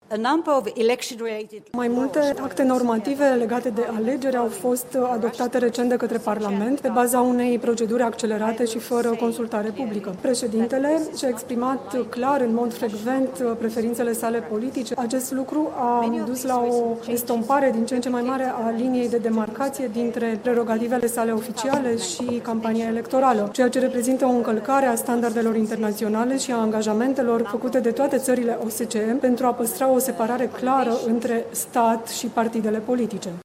Campania pentru alegerile din România a fost marcată de absenţa unui cod electoral unificat, care să cuprindă toate legile electorale – a apreciat, la o conferinţă de presă online, şefa Misiunii Speciale a Biroului pentru Instituţii Democratice şi Drepturile Omului al OSCE, Marianne Mikko.